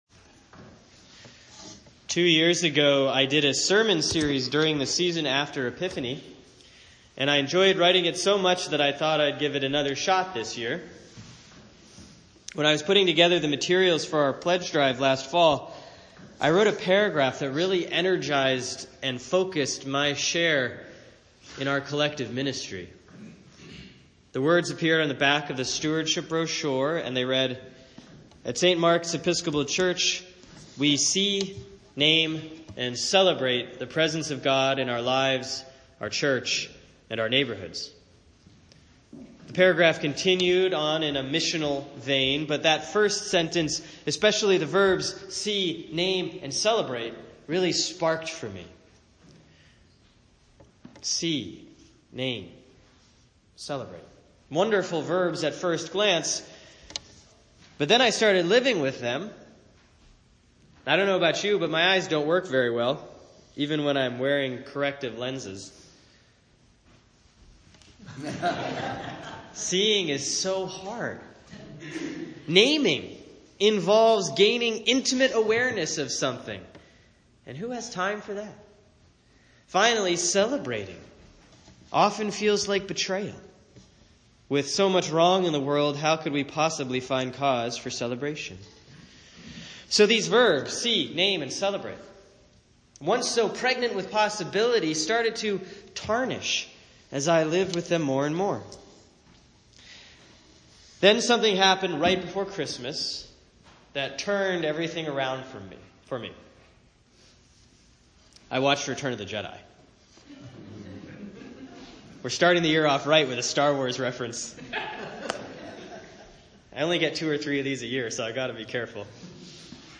Sermon for Sunday, January 8, 2017 || Epiphany 1A || Matthew 3:13-17